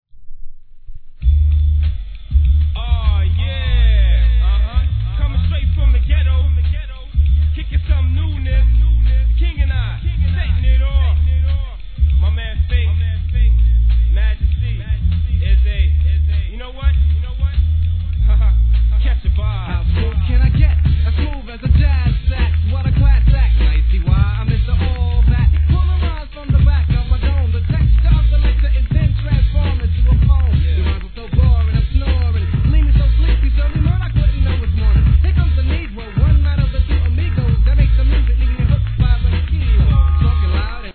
HIP HOP/R&B
チープな中にも感じ取れるものこそ、'90年代初期のHIPHOPでしょう！